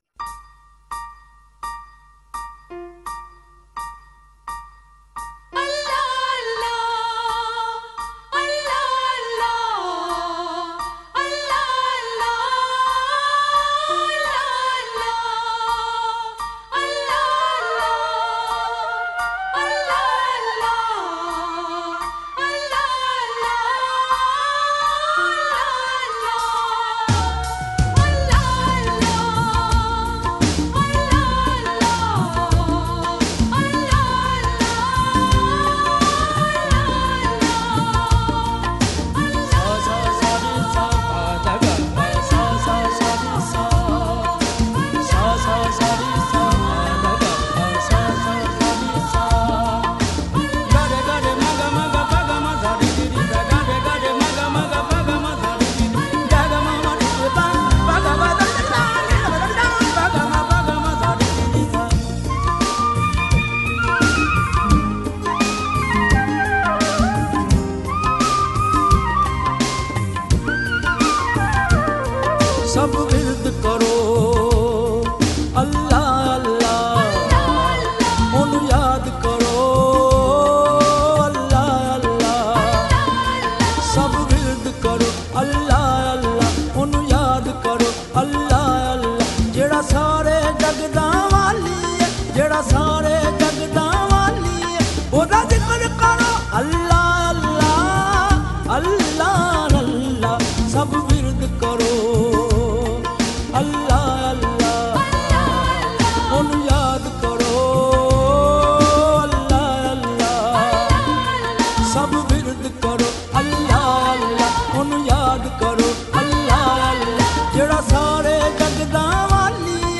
Naat and Naatiya Kalam